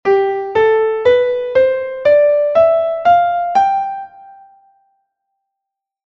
Escala Maior
4º tipo ou Mixolidia